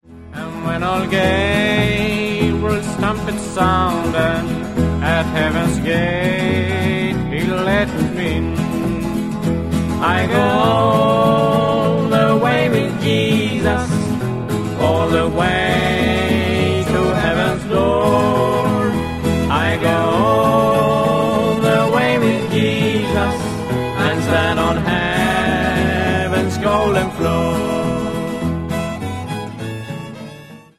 Recorded at a studio in Sveriges Radio 1972.